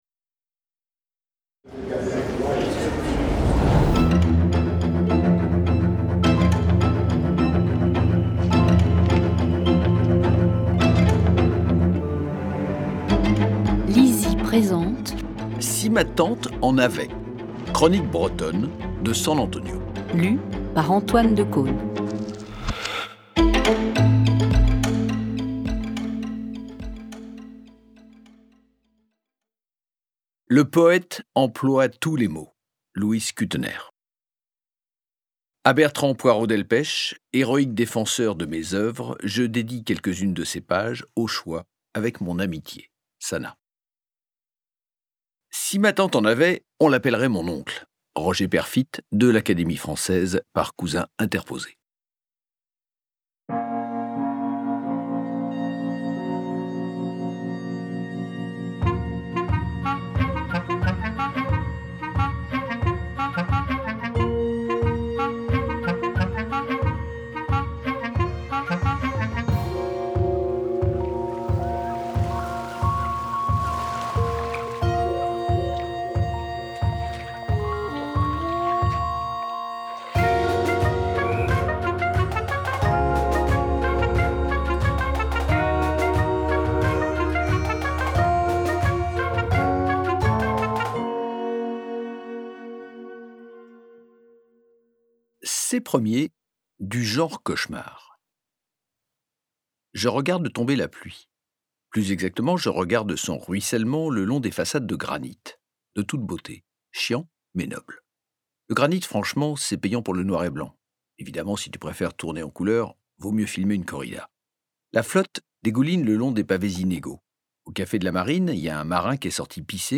Click for an excerpt - Si ma tante en avait de SAN-ANTONIO